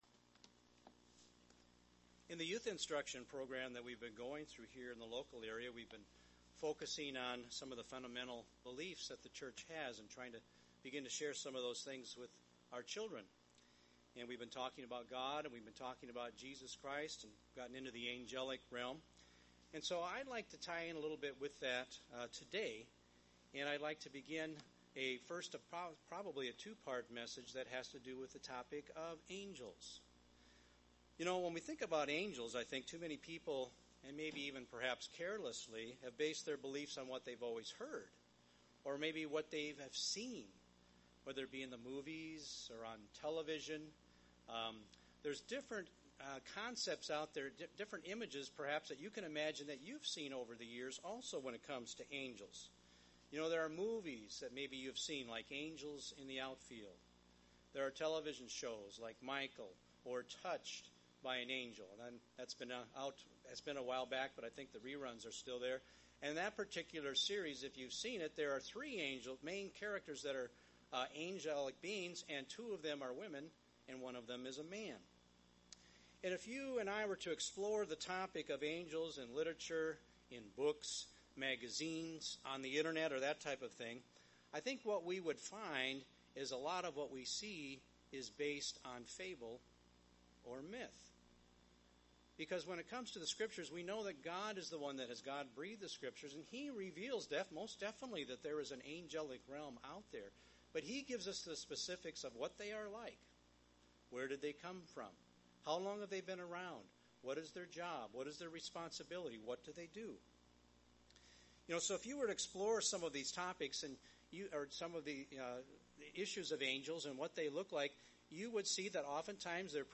There is a lot of misinformation about the spirit world. In this sermon, we look at the truth about angels.